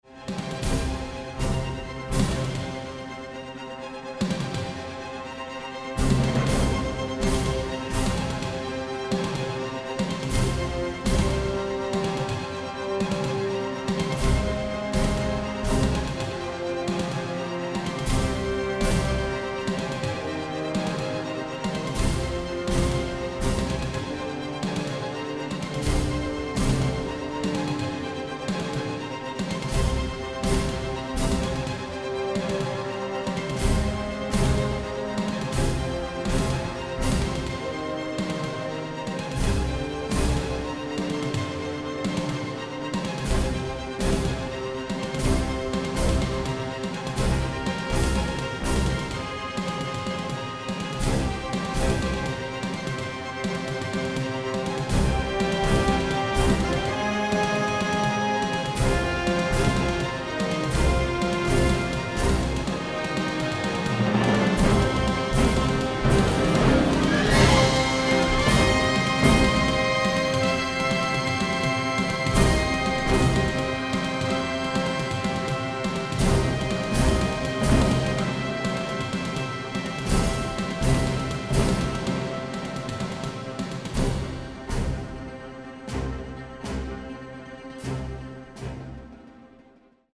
contemporary electronics